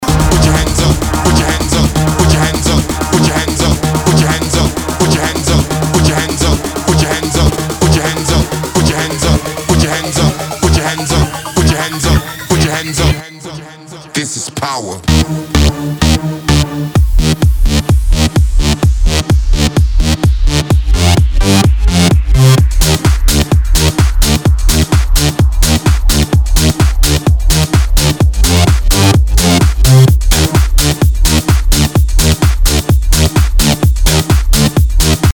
DJ and producer of tech house & house music
His style is unique and electrifying.